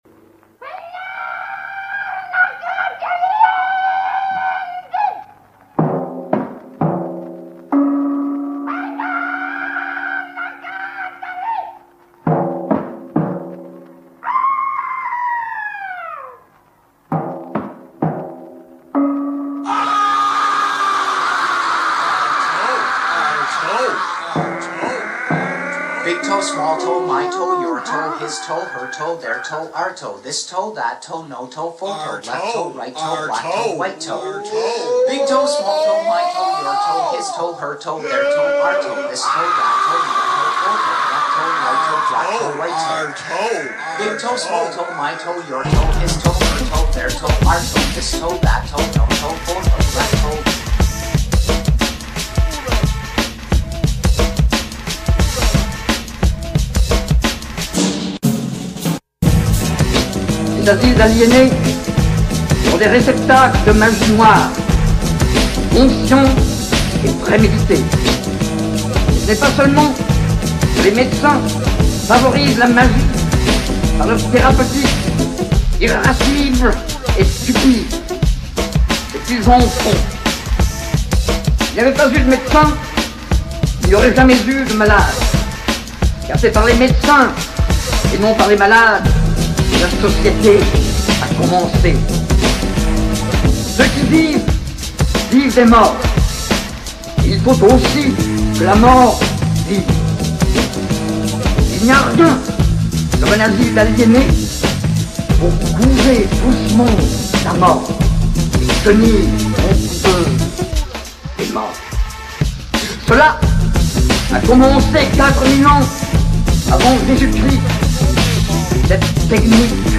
Voici les premiers essais d'expérimentation sonore, qui prendra bientôt une couleur davantage personnelle à mersure que je trouverai le temps de me faire aller la glotte et la carte de son au rythme des hurlements à la lune du grand gourou de la verve, j'ai nommé Claude Gauvreau. En attendant, je vous propose deux petits mix qui ont été présentés lors de ma dernière intervention 'pataphysique.